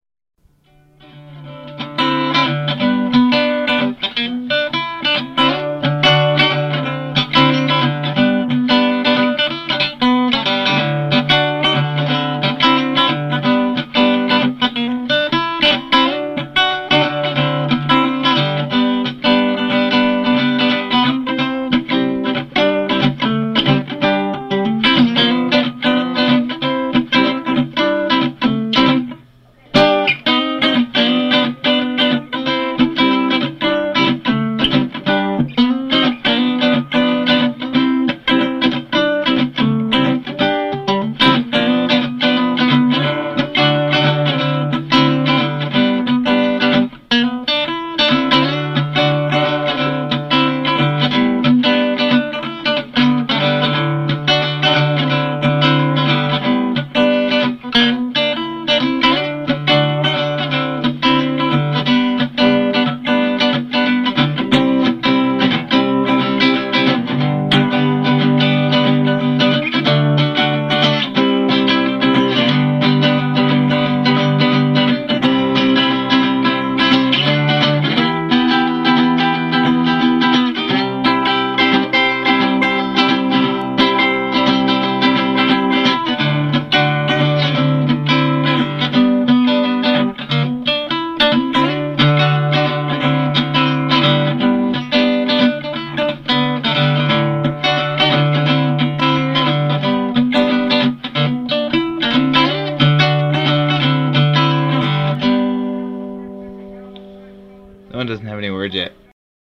The guitar riff that that this song is based on was literally lost and found. I recorded a sketch of it on a cassette tape and mailed it to my cousin before I left on my mission, and then forgot all about it.